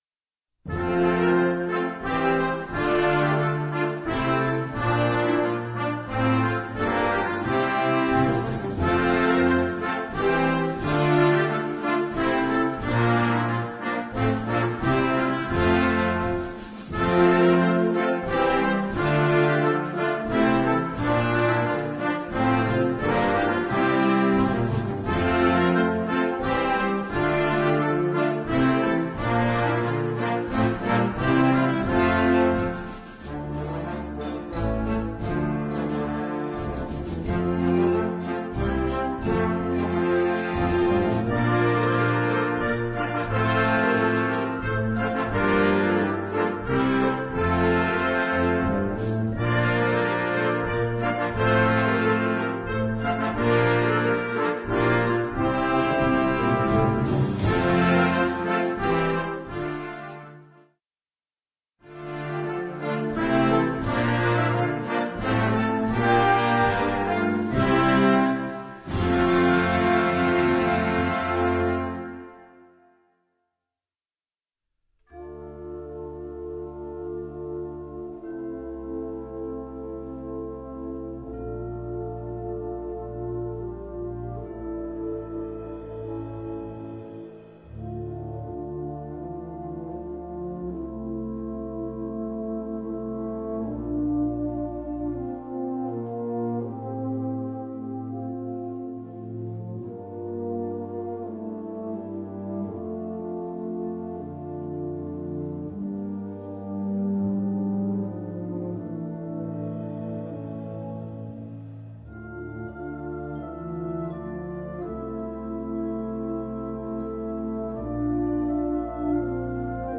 Gattung: Suite
Besetzung: Blasorchester
Der Schluss ist majestätisch und energisch.